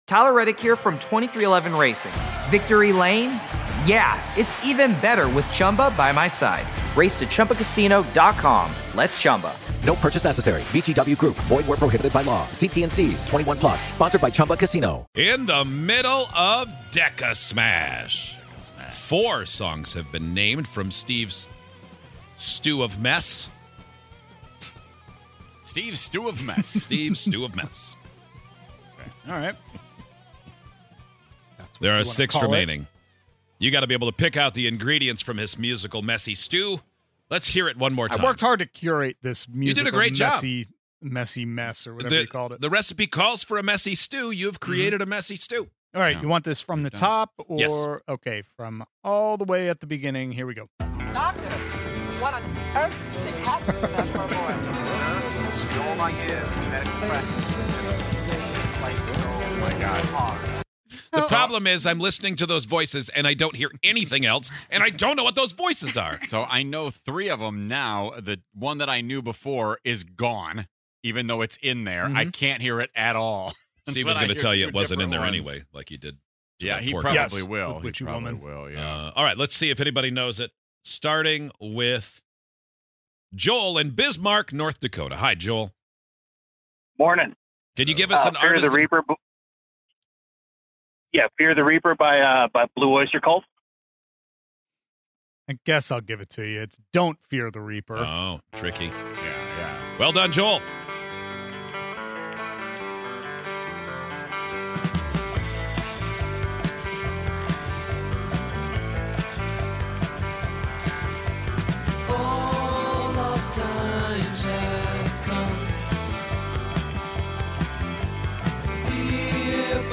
put together a list of ten songs, smashed them all together, and played them at the same time. Can you guess any of the 10 songs from DECASMASH?